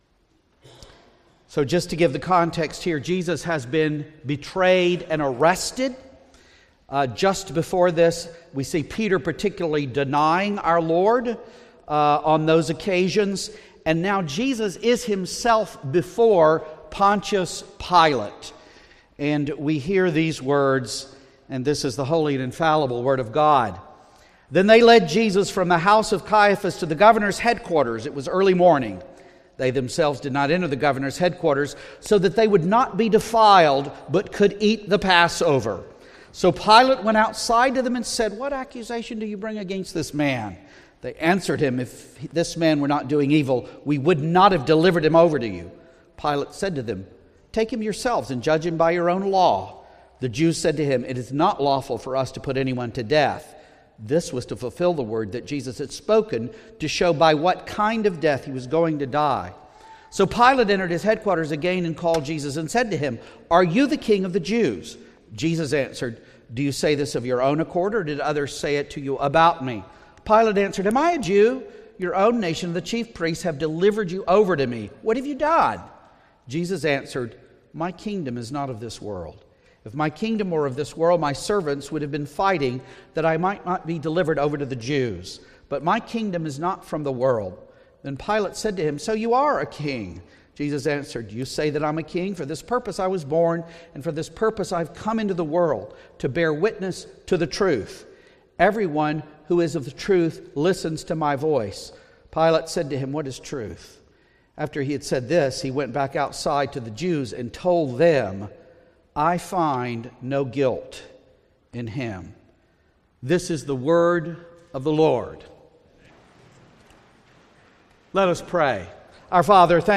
Presbytery Worship Sermon